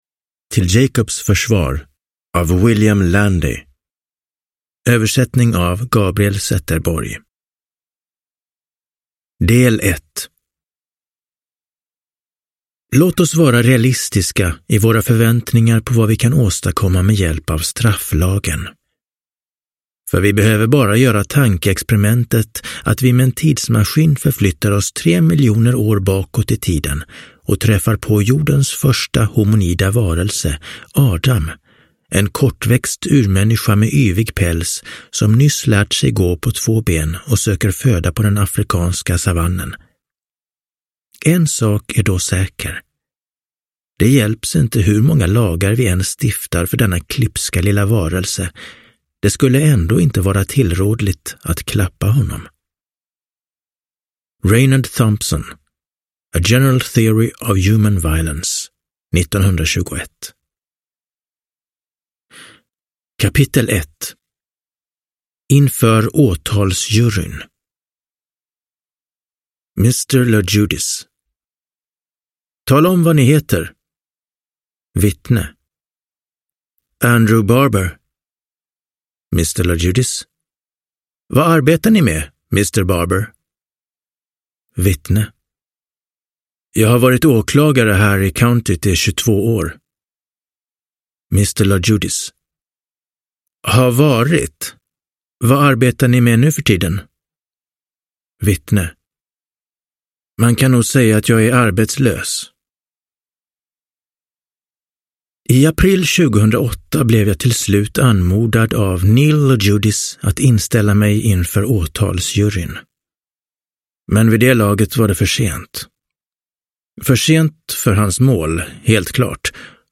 Till Jacobs försvar – Ljudbok – Laddas ner